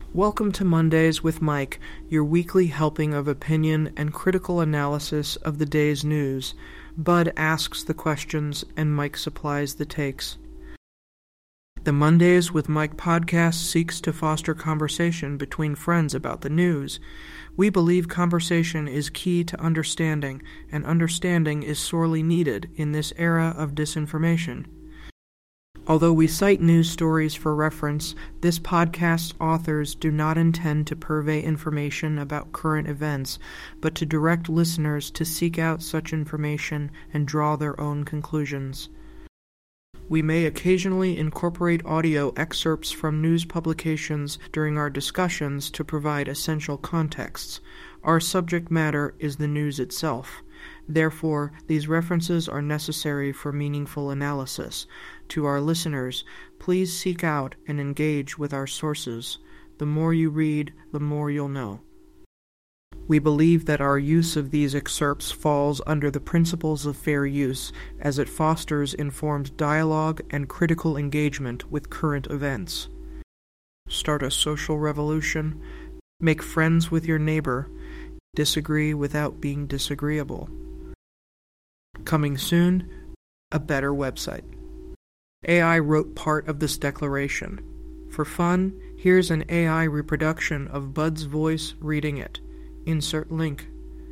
an AI reproduction